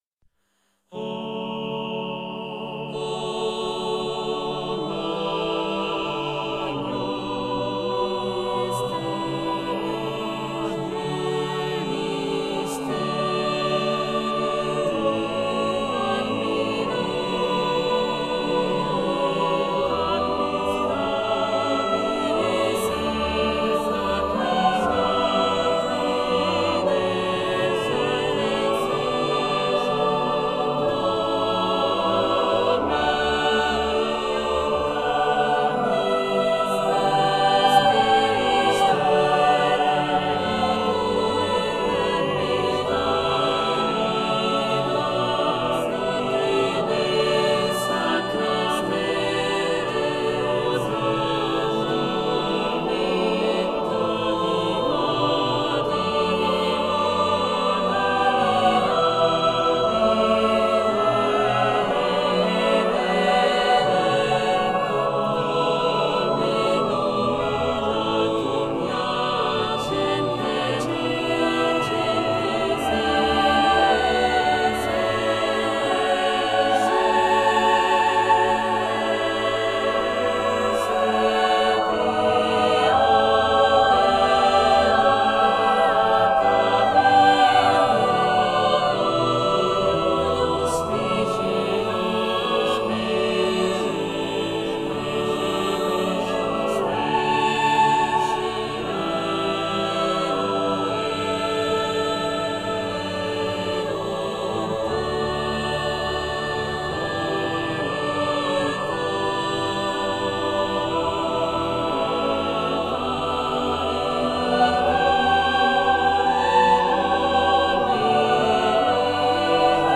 in Choral, Vocal
Uploaded an alternative version transposed one semitone below, since upon trying to redo the recording for the original's revised version with added-in corrections, the final result in the original key produced some unexplainable acoustic anomalies.
The rhythm is so well used here to create space for each voice's counterpoint so the whole thing is smooth and flowing.
The sonority is very rich and nothing feels wrong here!
The tone colors of the different voice types are different enough as to grant them distinctiveness even when they're really close together or even overlapping.